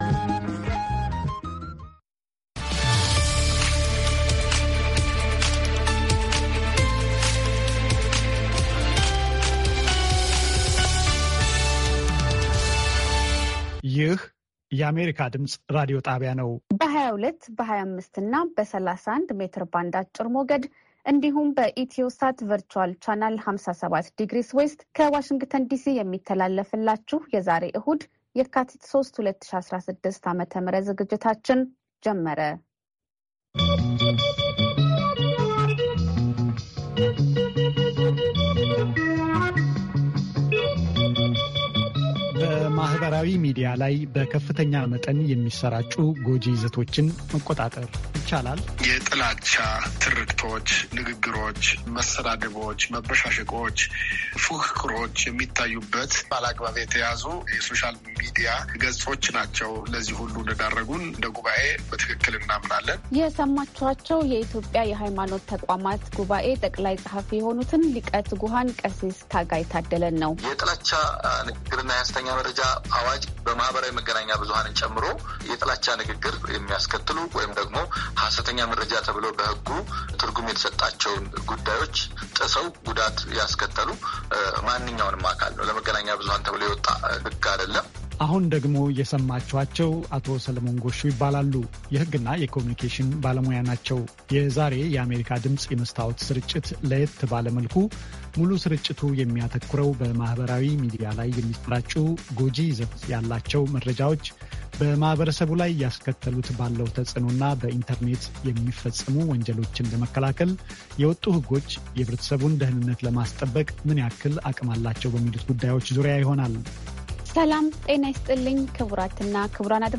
ዕሁድ፡- ከምሽቱ ሦስት ሰዓት የአማርኛ ዜና